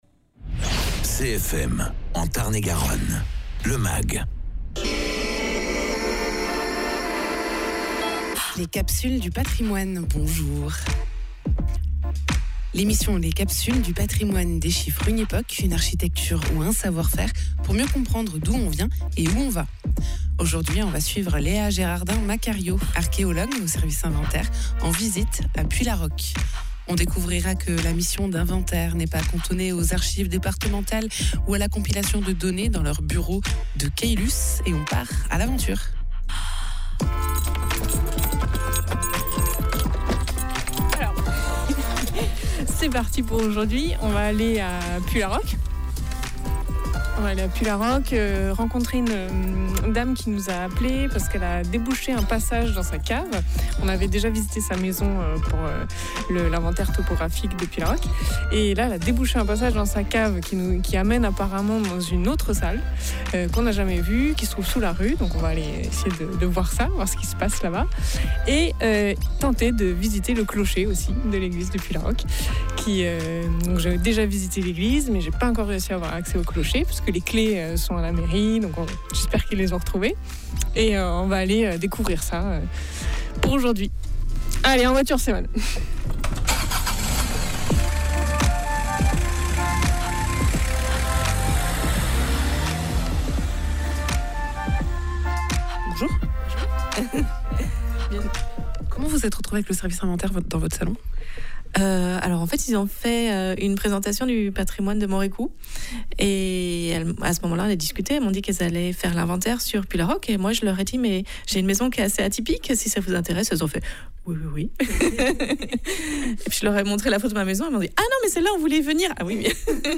Reportage à Puylaroque , en quête de patrimoine
Interviews